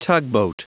Prononciation du mot tugboat en anglais (fichier audio)